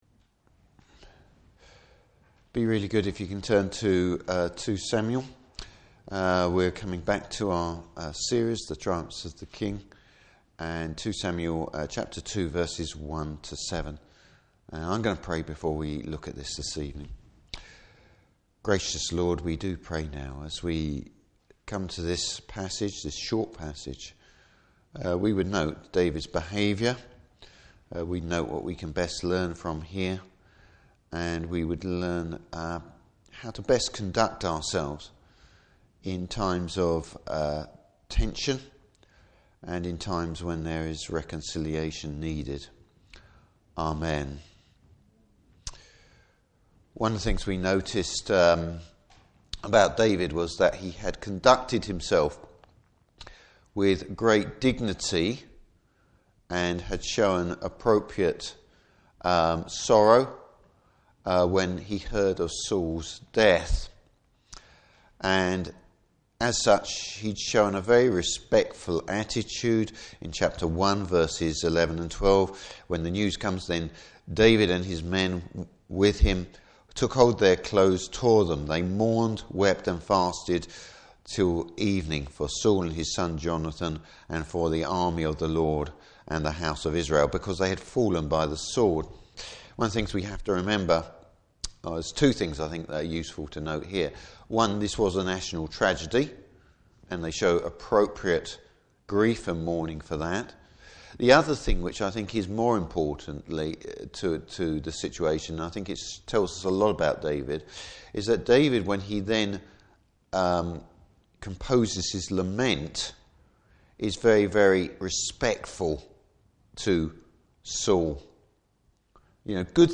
Service Type: Evening Service David seeks to win friends and influence people.